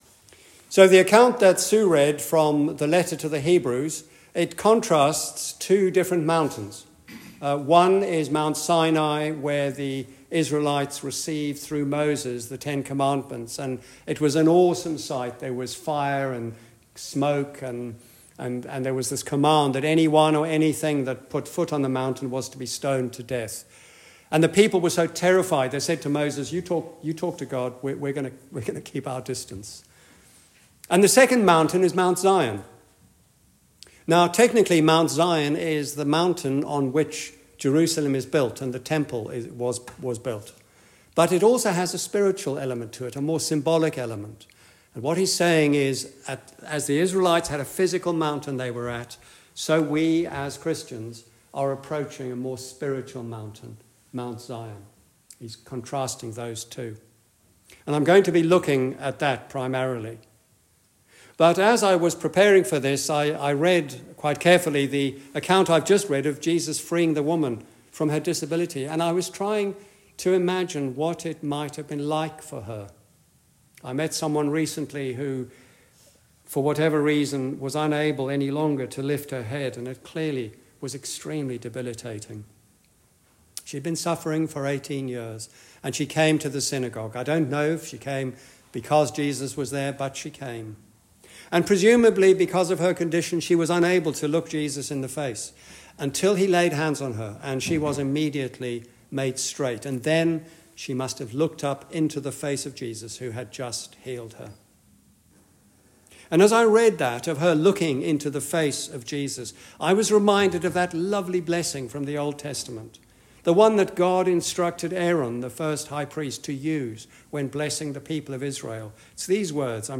Sermons - St Marys Church Wexham